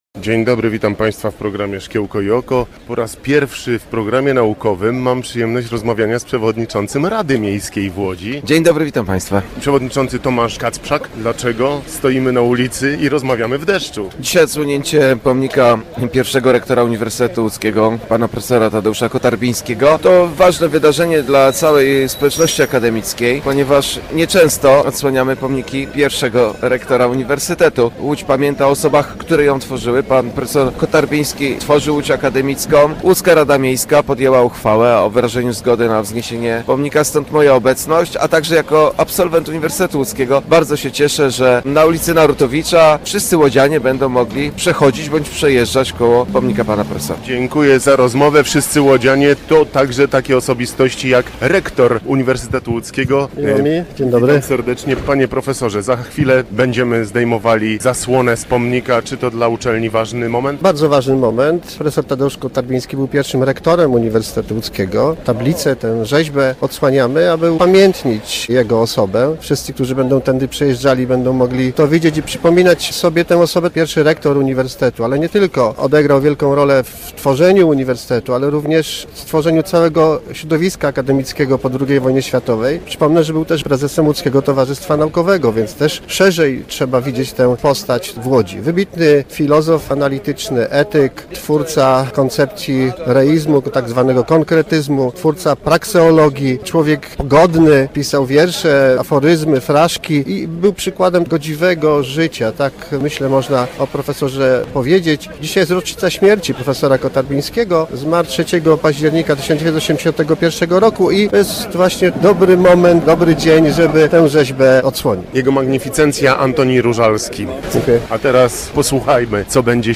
Pomimo deszczu przed wejściem do rektoratu Uniwersytetu Łódzkiego we wtorek (03.10) o godzinie 9.00, zgromadziło się grono przedstawicieli władz i najważniejszych łódzkich uczelni wyższych. Powodem było odsłonięcie pomnika pierwszego rektora UŁ, profesora Tadeusza Kotarbińskiego.